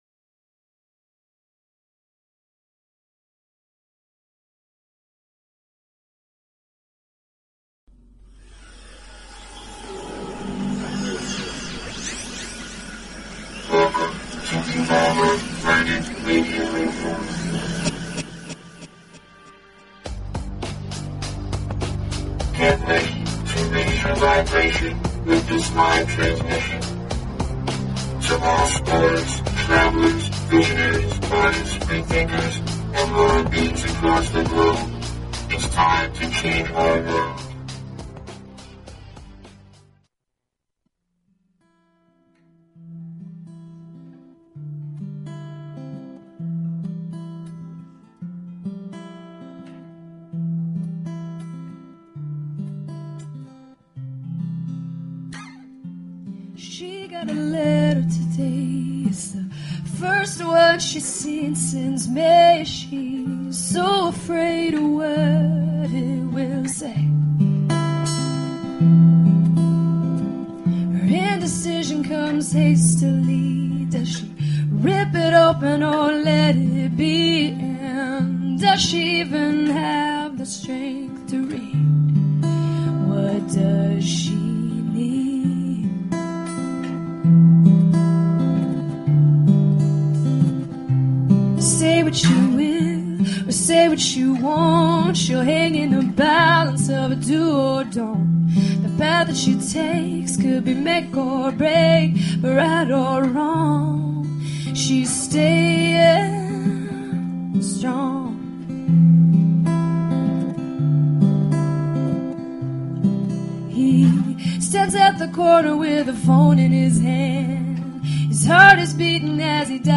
Talk Show Episode, Audio Podcast, Evolver_Planet_Radio and Courtesy of BBS Radio on , show guests , about , categorized as